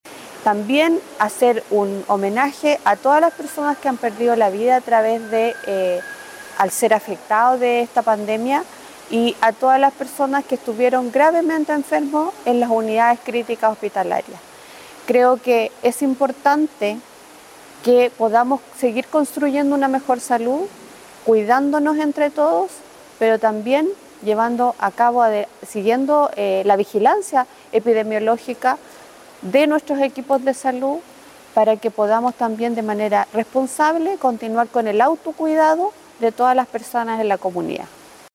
La Seremi de Salud también señaló que no se debe olvidar a todas las personas que fallecieron a causa de la pandemia de Covid-19, y de esa forma mantener la conciencia del autocuidado.